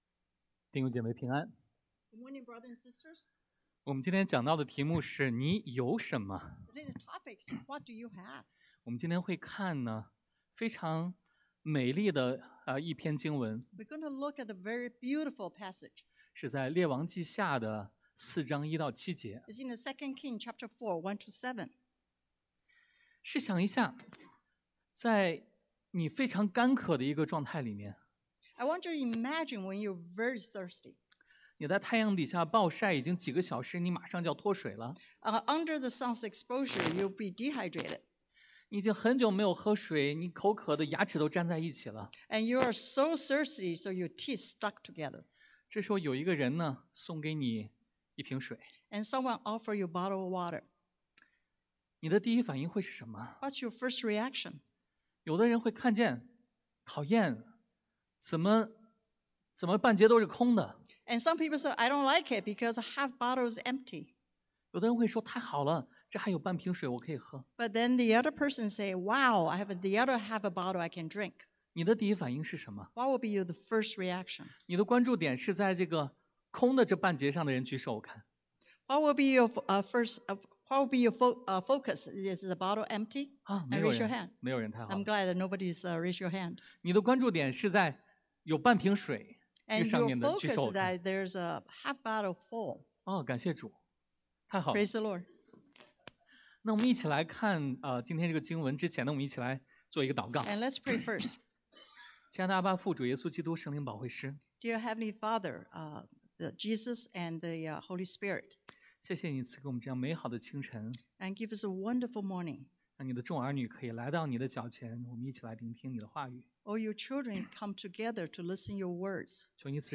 Passage: 列王纪下2 Kings 4:1-7 Service Type: Sunday AM What do you have?